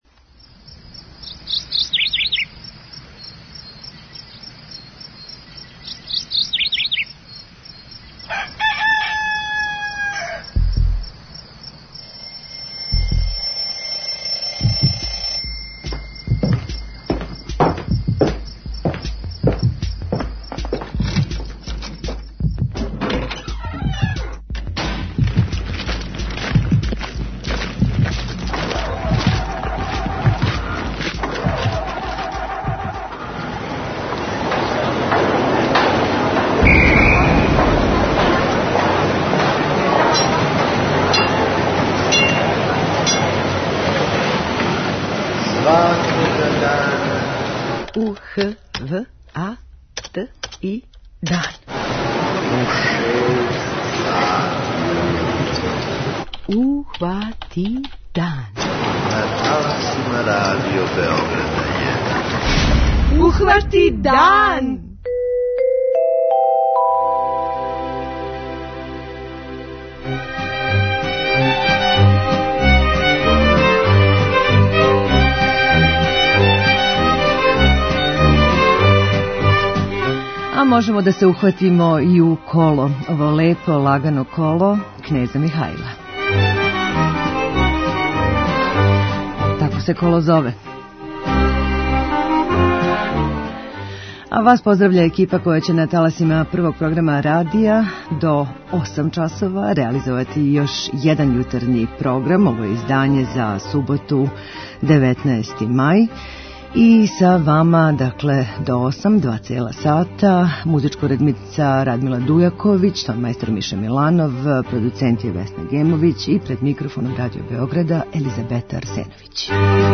преузми : 21.54 MB Ухвати дан Autor: Група аутора Јутарњи програм Радио Београда 1!